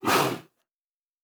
Monster_02_Attack.wav